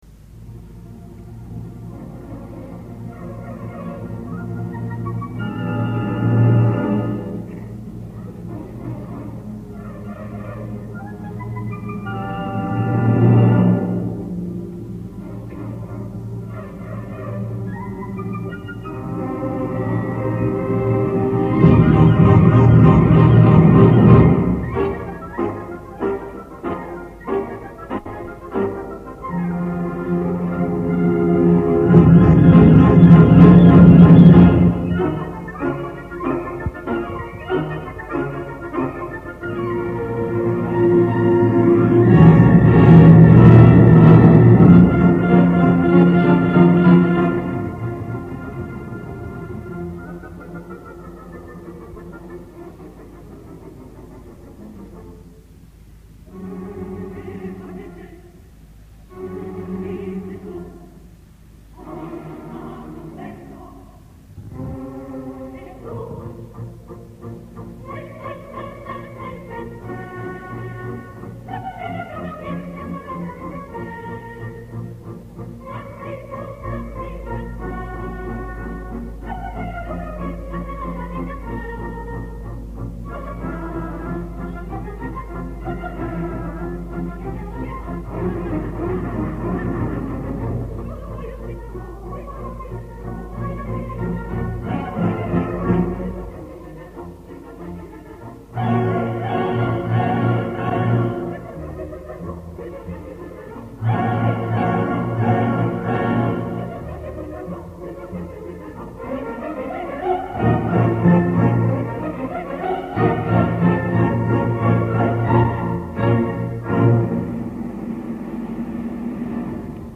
Coro del Teatro Comunale di Firenze, dir. Riccardo Muti (1975)